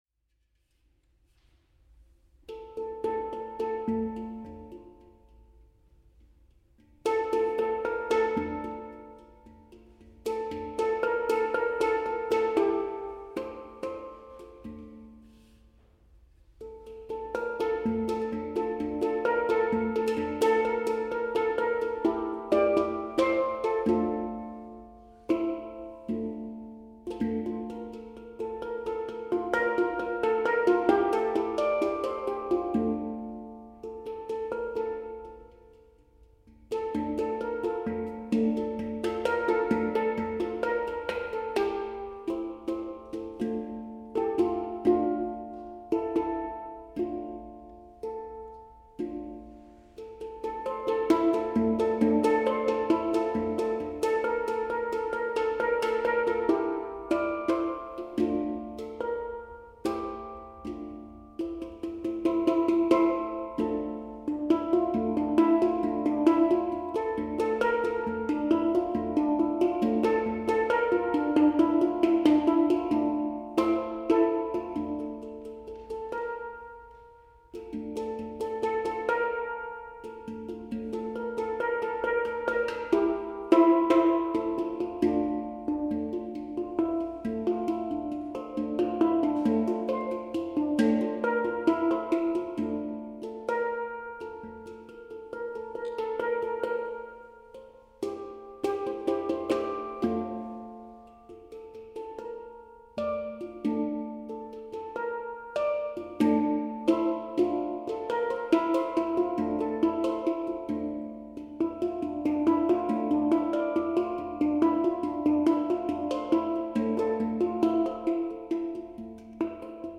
PERCUSSIONIST
HANG